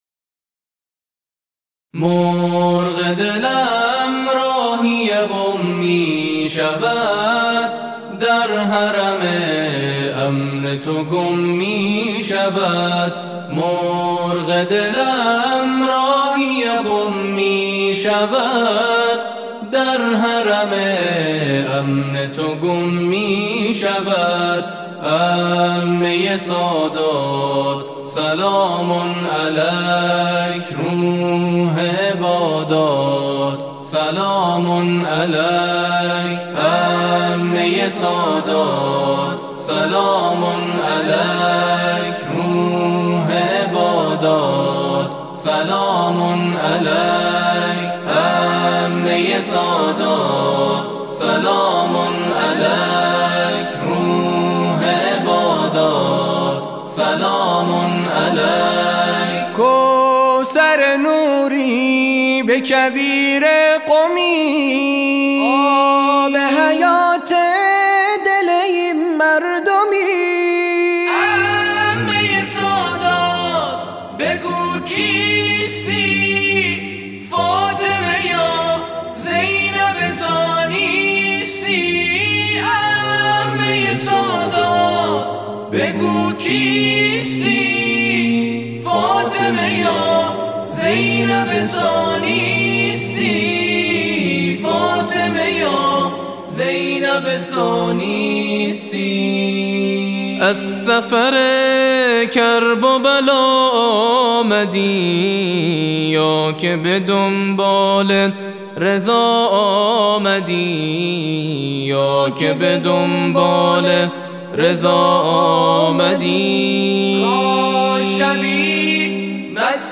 نغمات آئینی